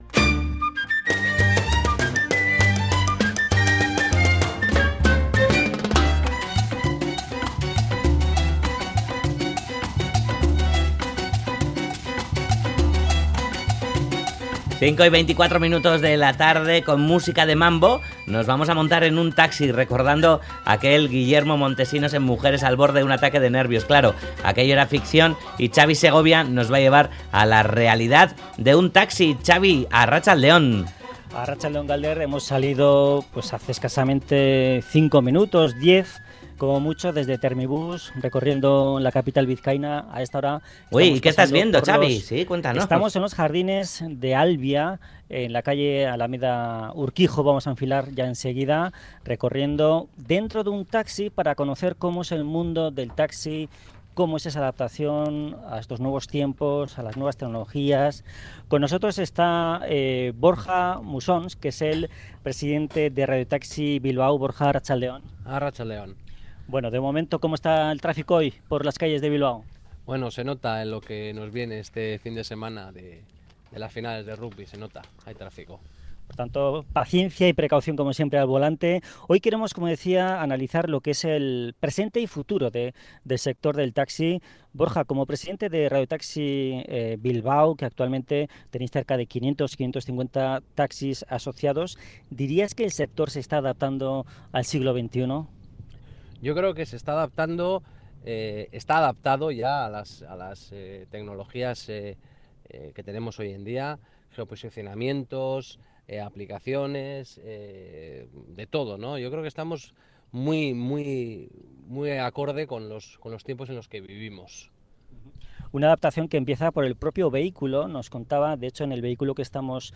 Audio: Viajamos en directo en uno de los vehículos adaptados al siglo XXI de Radio Taxi Bilbao para conocer cómo se están preparando los taxistas de Bilbao ante las nueva demandas sociales.